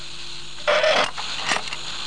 clanger.mp3